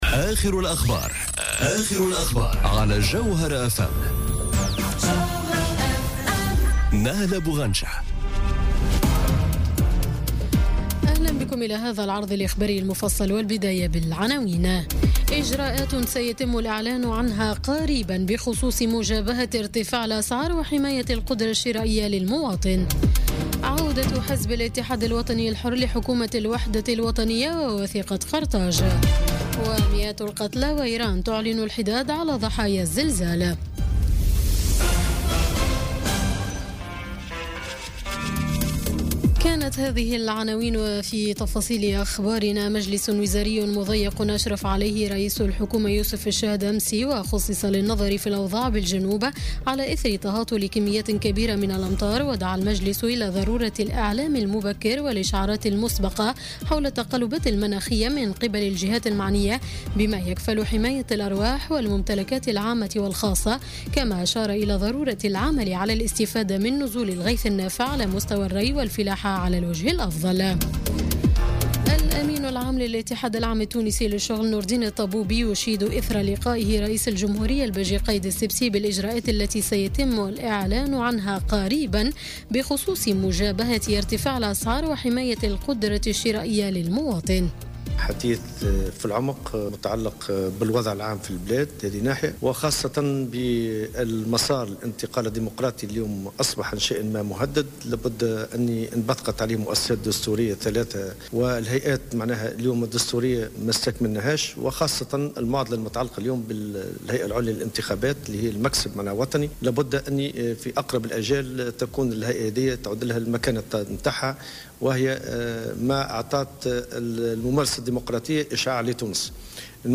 نشرة أخبار منتصف الليل ليوم الثلاثاء 14 نوفمبر 2017